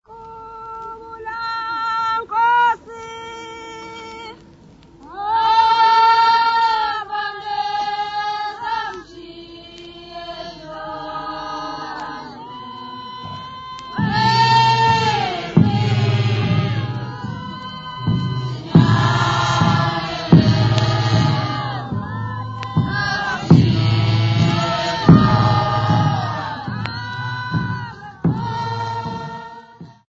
Congregation of the Church of Nazareth (Shembe) (Performer)
Separatist Hymn
Izigulu bass drum
Nhlangakazi Ndwedwe
Separatist Hymn by the Congregation of the Church of Nazareth, (Shembe), accompanied by izigulu bass drum
Hugh Tracey (Recorded by)